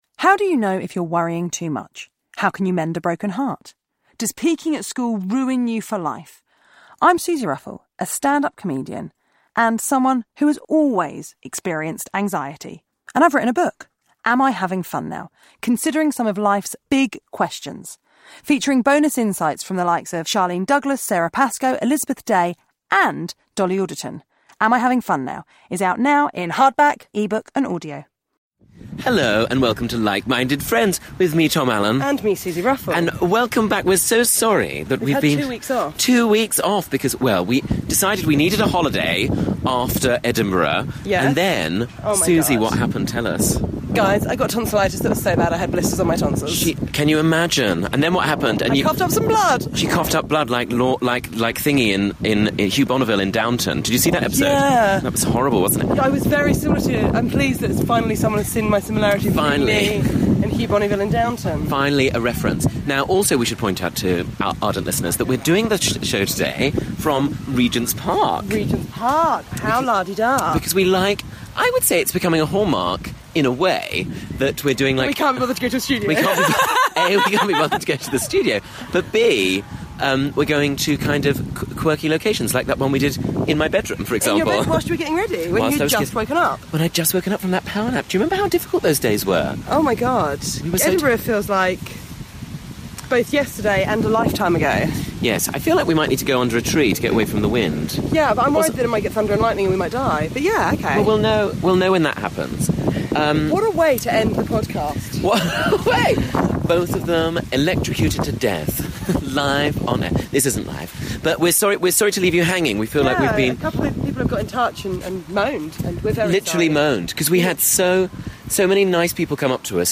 Tom and Suzi take a stroll in Regents Park for a well overdue catch up...